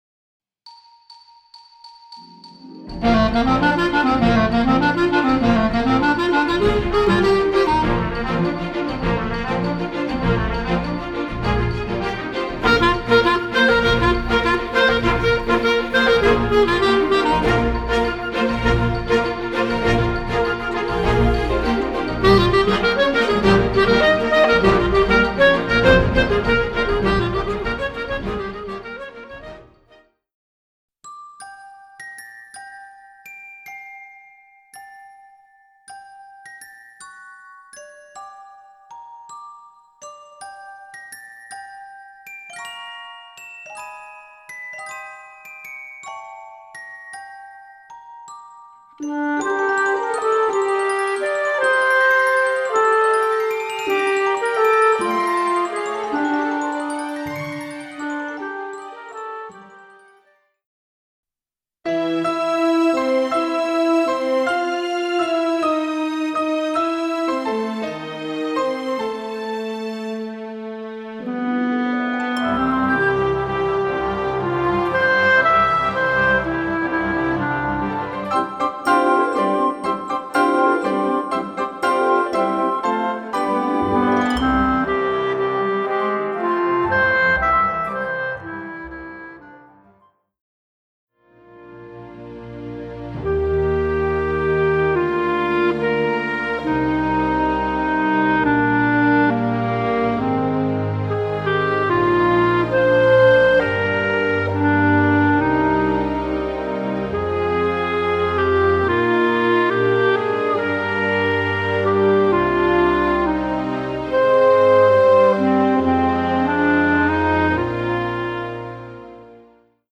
Voicing: Bb Clarinet w/ Audio